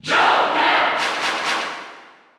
Joker_Cheer_French_NTSC_SSBU.ogg.mp3